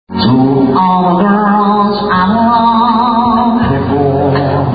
Vocal impressions of Sinatra, cher, and Rodney Dangerfield.
Willie-Nelson-Impersonator-Impressionist-Comedy-Corporate-Entertainment-short.mp3